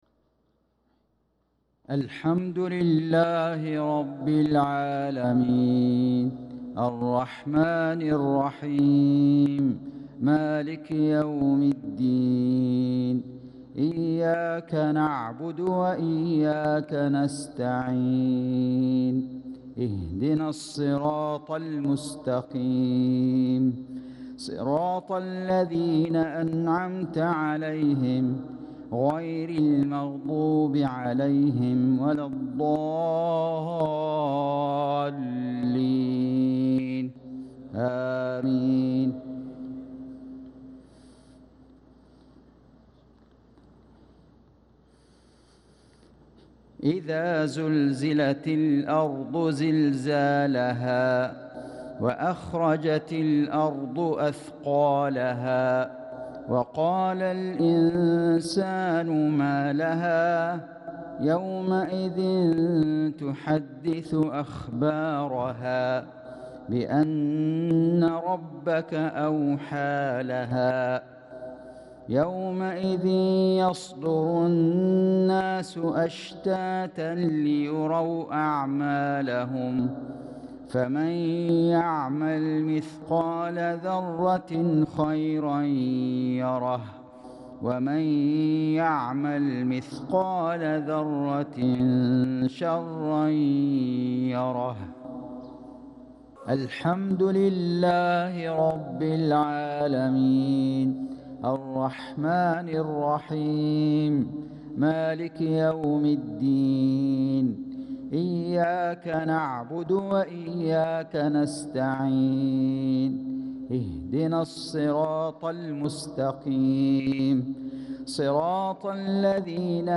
صلاة المغرب للقارئ فيصل غزاوي 21 ذو الحجة 1445 هـ
تِلَاوَات الْحَرَمَيْن .